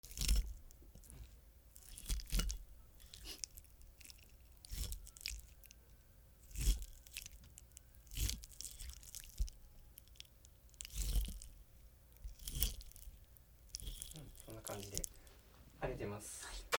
プラスチック食器で肉を切る 4
MKH416